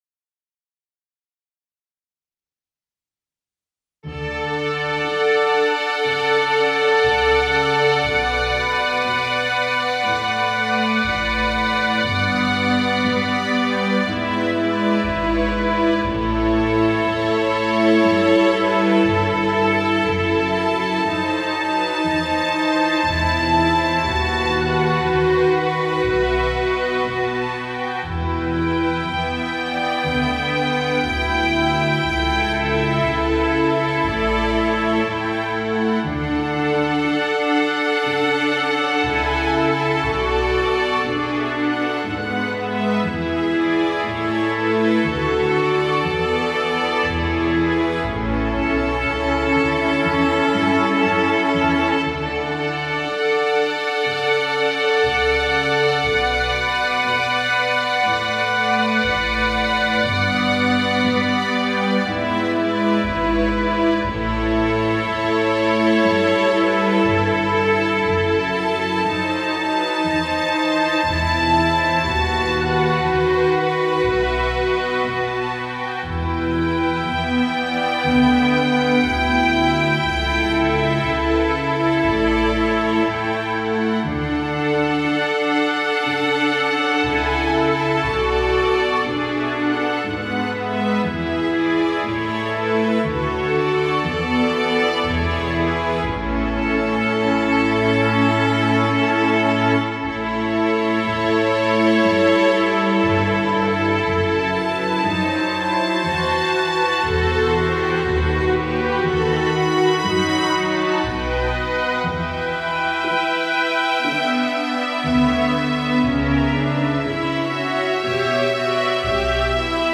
レコーディング実験
※既成MIDIデータ使用。
レコーディング及びミキシング、マスタリングは24bit/96kHz。